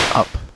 Recovered signal (M=4, Mu=0.005)
• There wasn't any perceptible difference between recovered signals with different Mu.